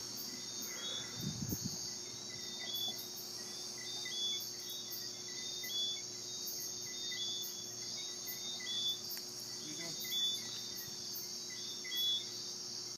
Saturday nights on Saba can, on occasion, be remarkably quiet. We were walking home from Windwardside at 9:30pm one night–prime time–and my brother recorded the ubiquitous teeny singing tree frogs whistling in the night, instantly familiar to those who’ve been there, slightly haunting and sweet to those who haven’t. Take a listen.
Saba Night Sounds